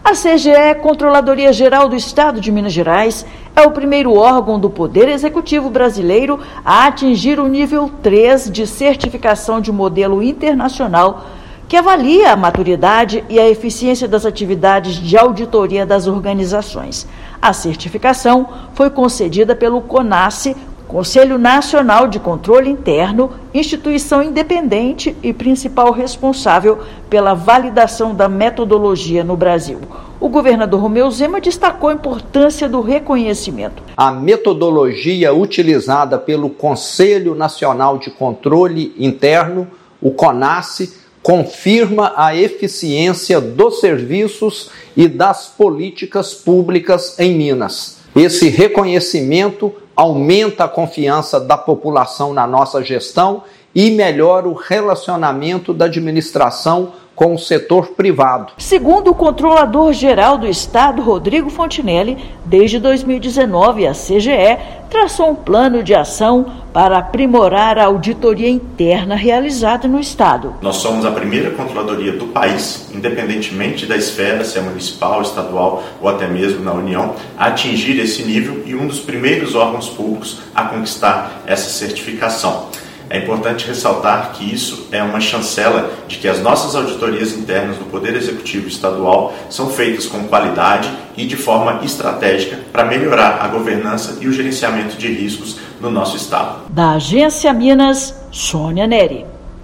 Resultado consolida o controle interno da administração estadual mineira como referência nacional e internacional. Ouça matéria de rádio.